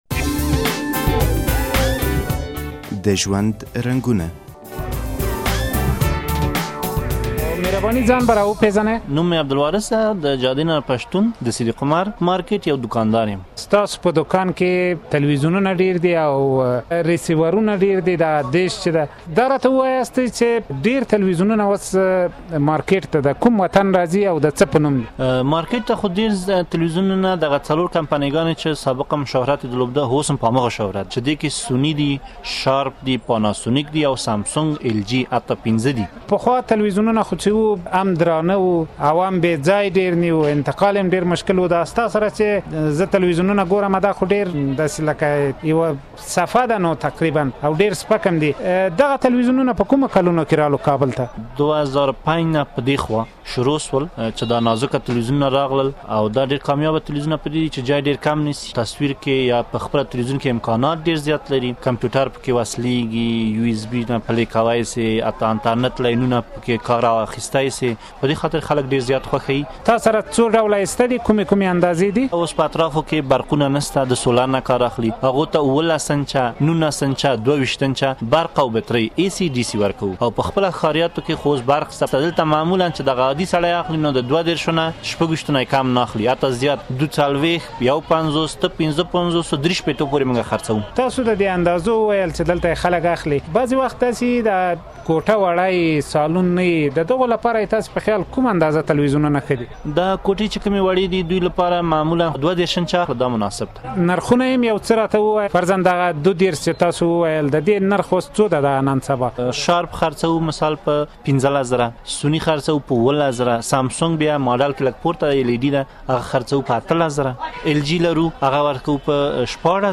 د کابل په صدیق عمر ماررکیټ کې مو یو دوکاندار غږولی چې د وارد شوو تلویزونونو په اره غږیږي :